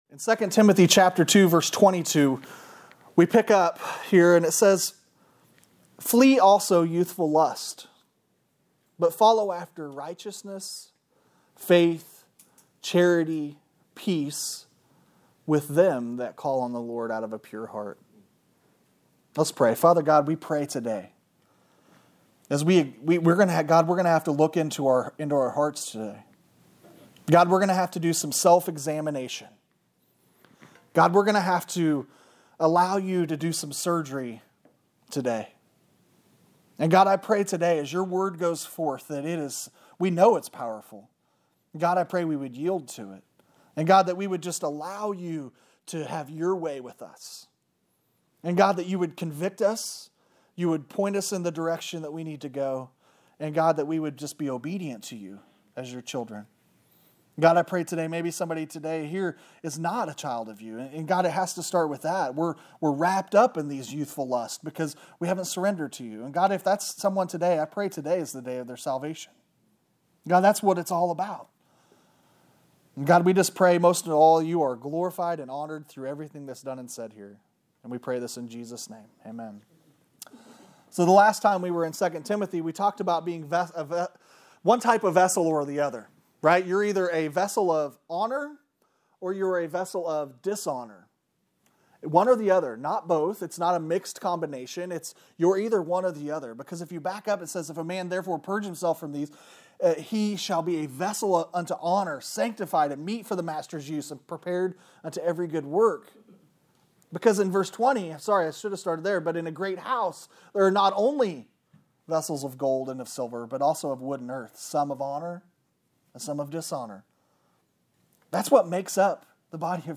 Sermon on 2Timothy 2:22